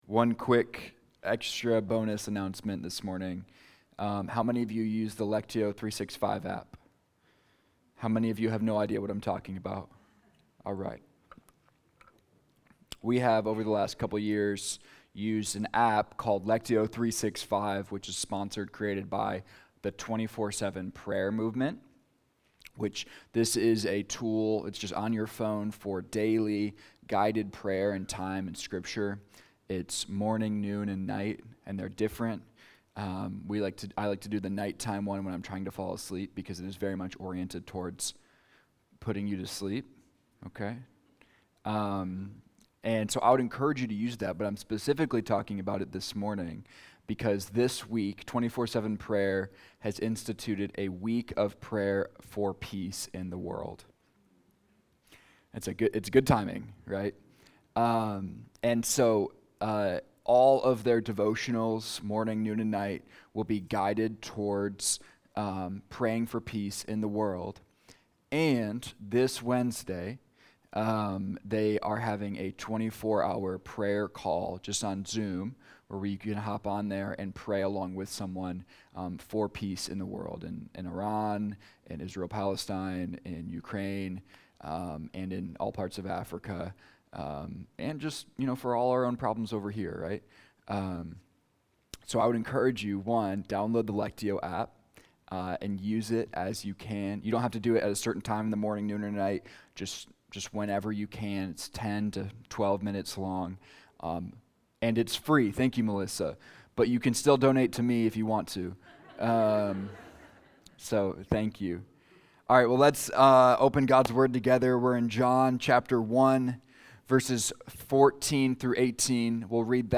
Hear recorded versions of our Sunday sermons at your leisure, in the comfort of your own personal space.
Service Type: Sunday Morning